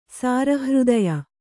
♪ sāra hřdaya